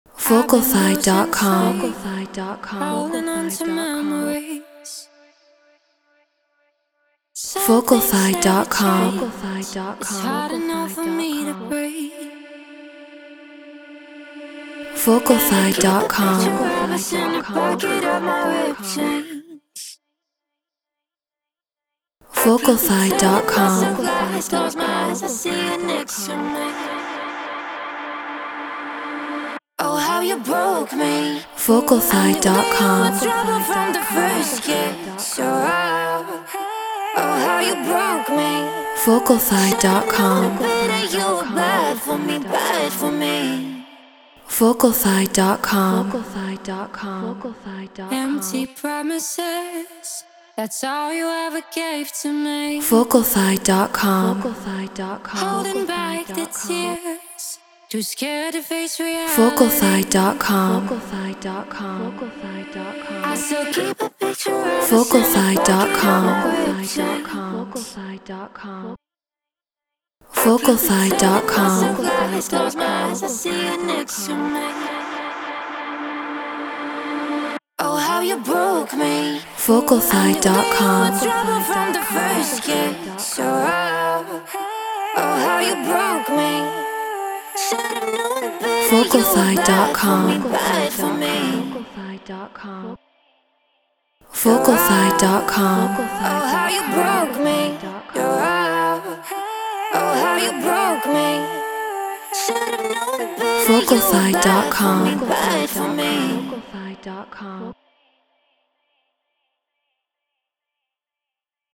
EDM 130 BPM Dmin